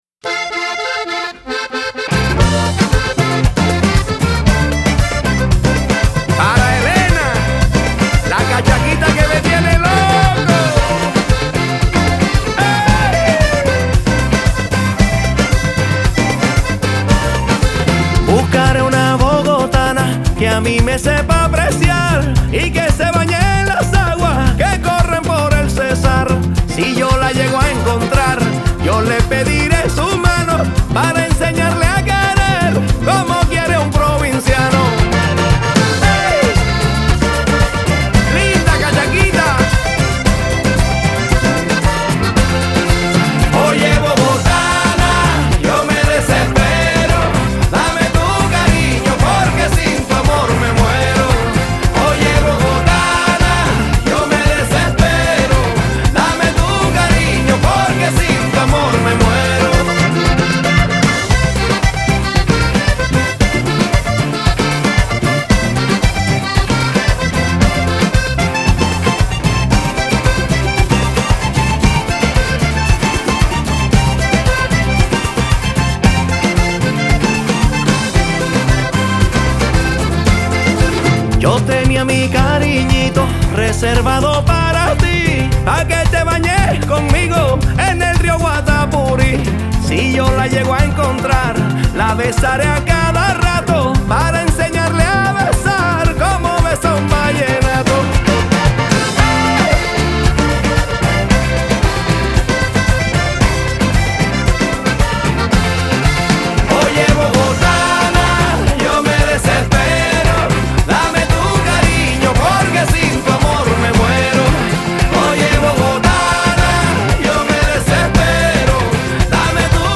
Vallenato